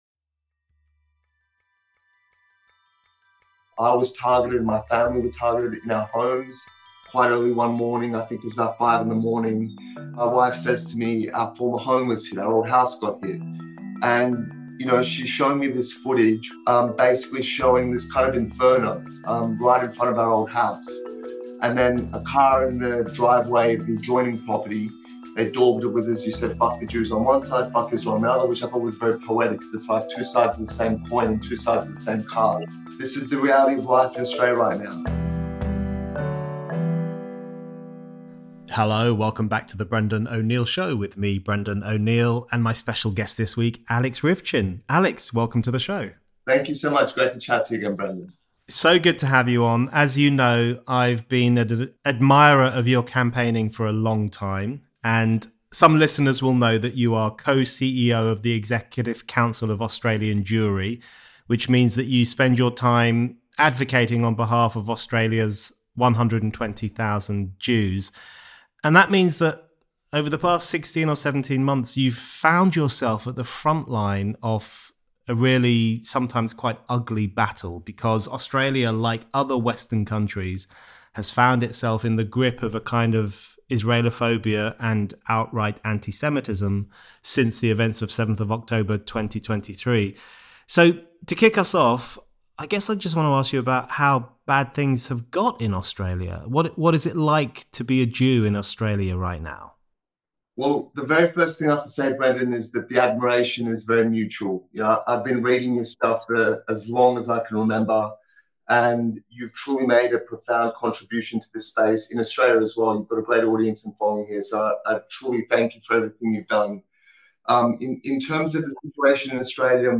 Alex Ryvchin – CEO of the Executive Council of Australian Jewry – is the latest guest on The Brendan O’Neill Show.